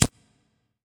また荷物が動いた時に音が出るようにしました。
move.wav